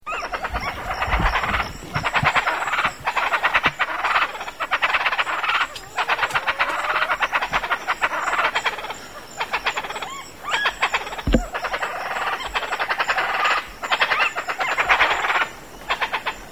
Ortalis canicollis - Charatá
DIMORFISMO SEXUAL ADULTOS El macho canta una octava más baja que la hembra